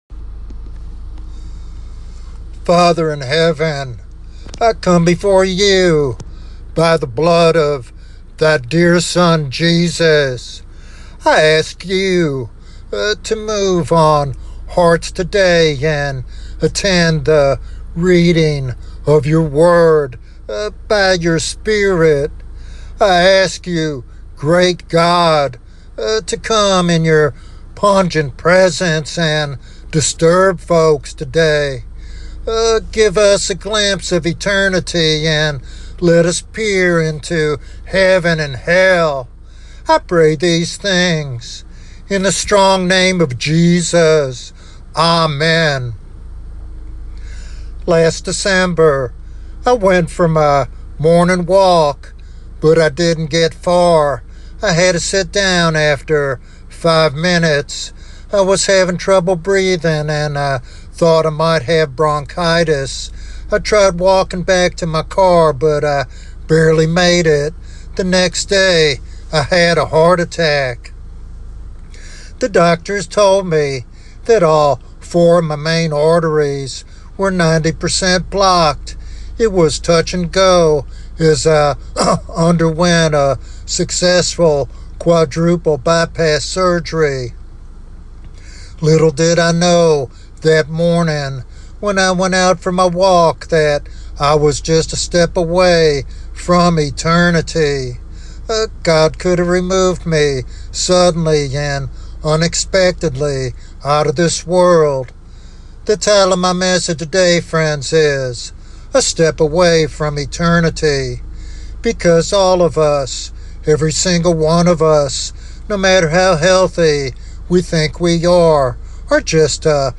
He emphasizes the urgency of accepting Christ’s sacrifice for salvation and living a life that honors God. This sermon challenges believers and seekers alike to consider their eternal destiny and respond to God's call without delay.